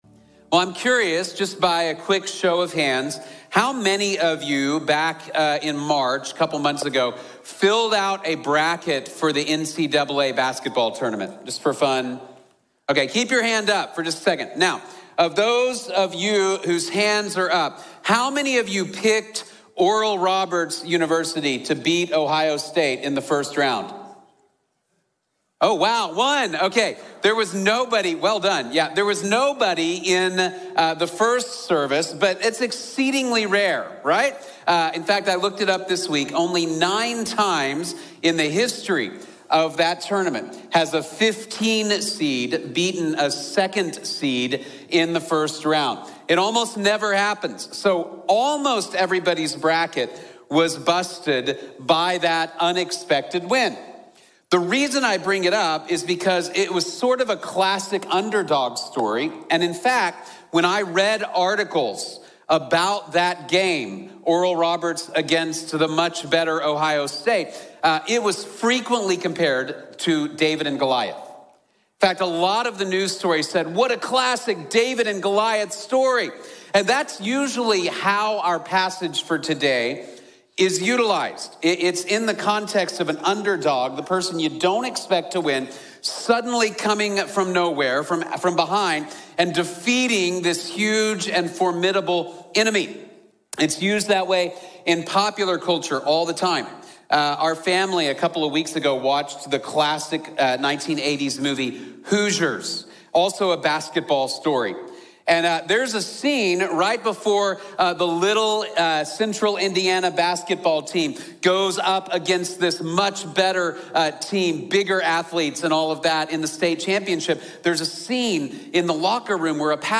God Always Wins | Sermon | Grace Bible Church